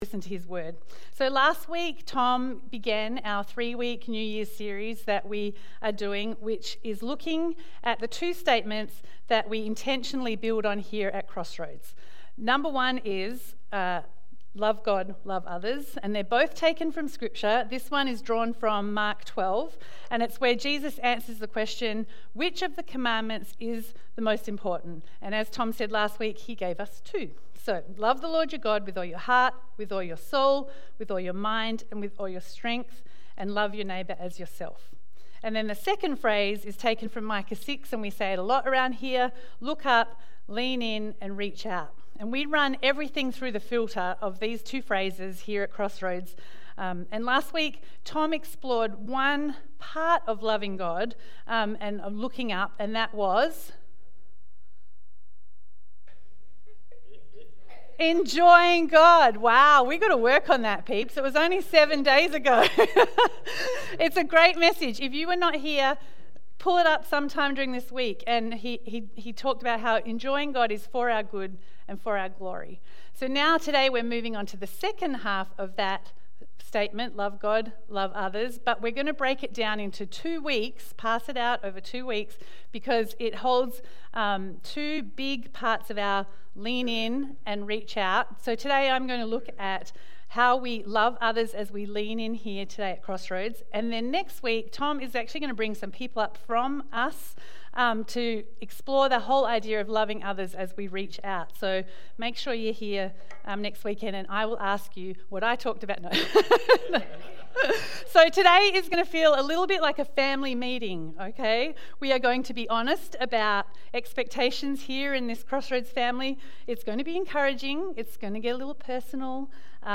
Timestamps: Welcome & Worship: 3:01 Spiritual Practice: 13:50 Worship: 18:27 Sermon: 31:17 Announcements: 57:44 Song List: I Believe The Way (New Horizon) Yet Not I But Through Christ In Me Build My Life